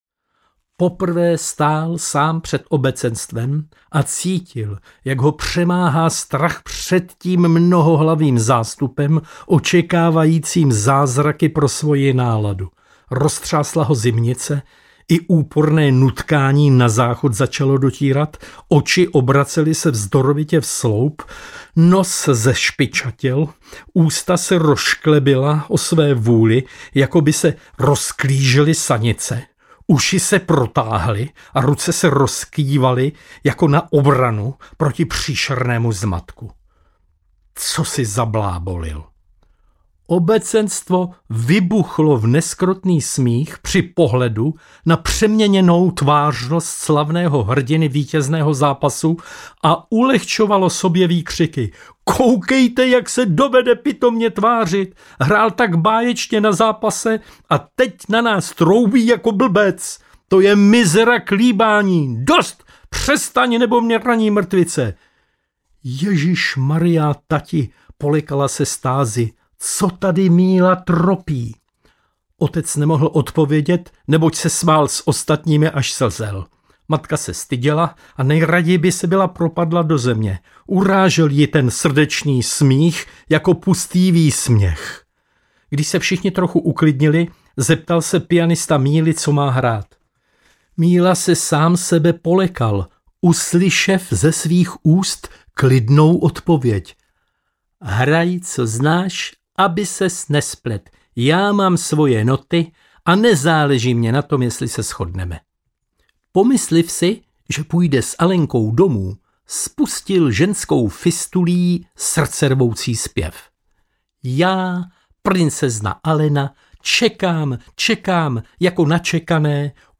Ukázka z knihy
kral-komiku-vlasta-burian-audiokniha